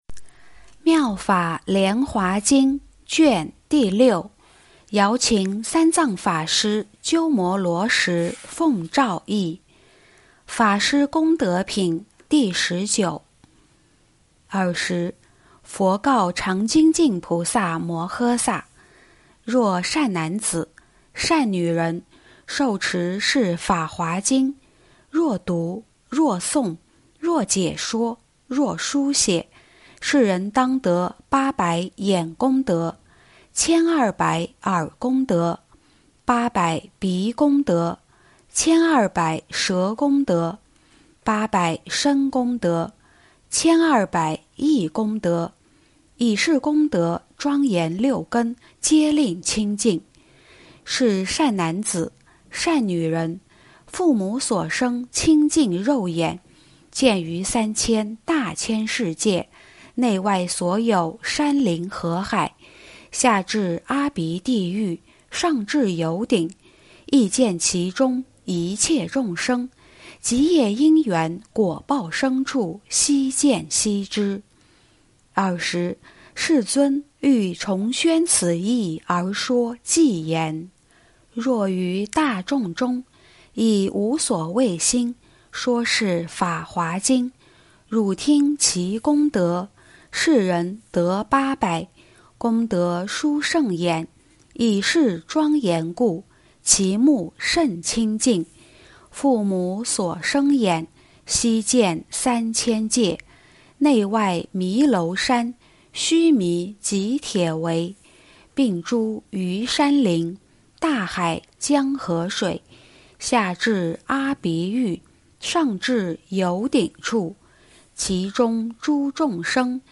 《妙法莲花经》法师功德品 第十九--佚名 经忏 《妙法莲花经》法师功德品 第十九--佚名 点我： 标签: 佛音 经忏 佛教音乐 返回列表 上一篇： 《妙法莲华经》分别功德品第十七--佚名 下一篇： 《妙法莲华经》常不轻菩萨品第二十--佚名 相关文章 纯真天使--佛教音乐 纯真天使--佛教音乐...